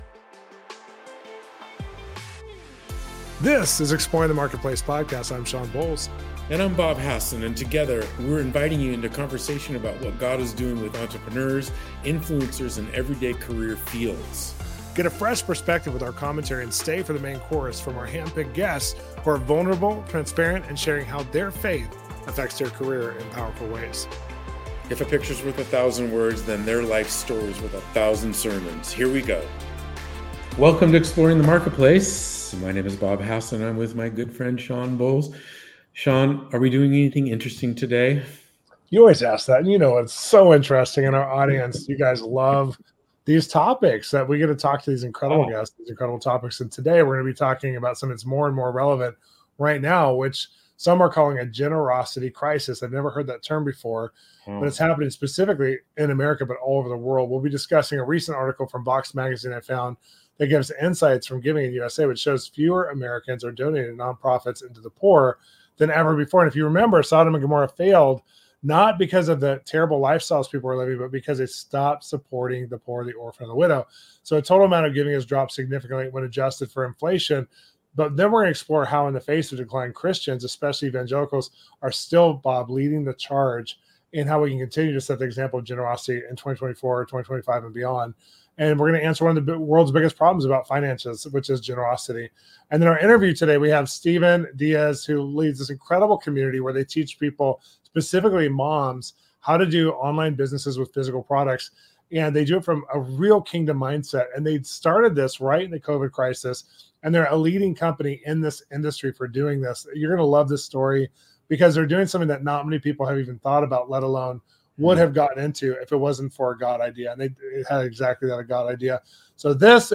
Come join the conversation!